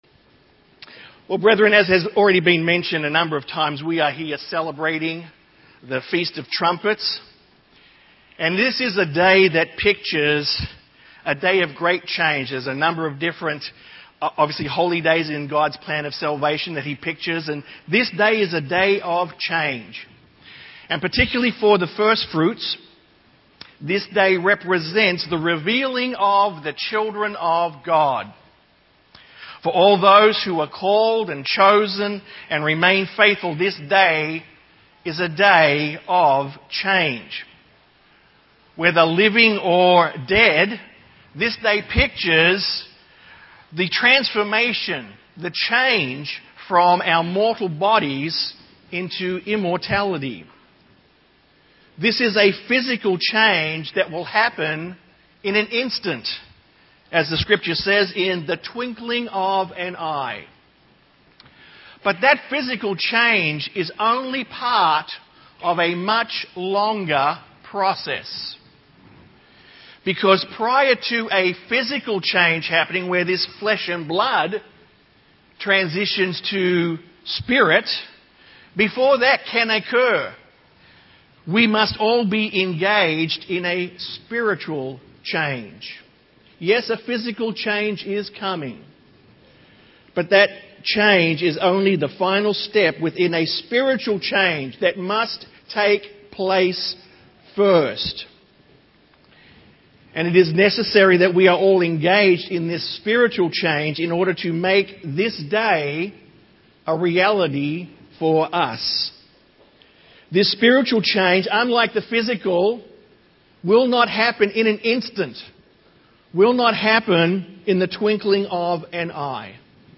There must also a change of our minds and that is a process that is presented in this message given on the Feast of Trumpets.
Sermons